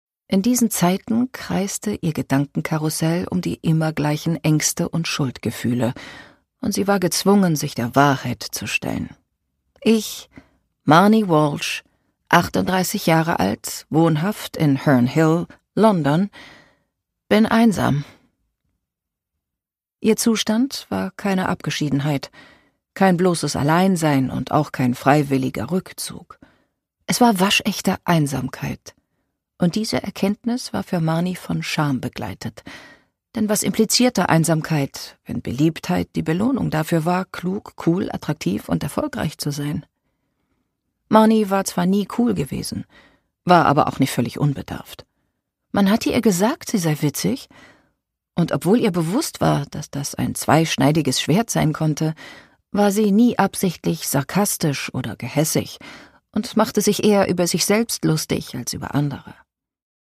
David Nicholls: Zwei in einem Leben (Ungekürzte Lesung)
Produkttyp: Hörbuch-Download